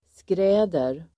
Ladda ner uttalet
Uttal: [skr'ä:der]